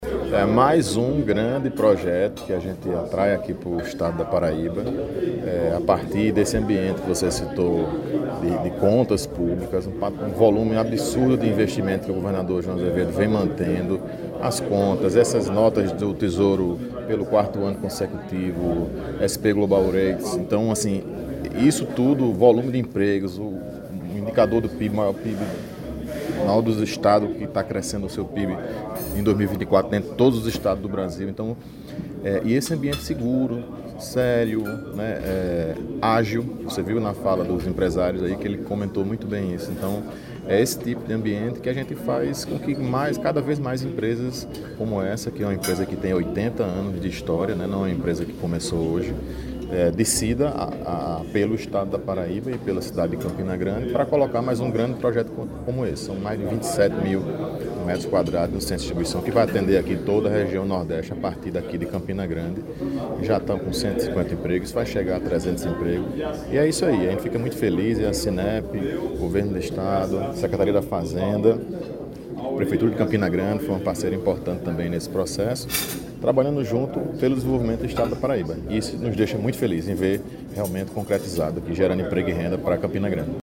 Governador e Prefeito de CG se encontram no evento de inauguração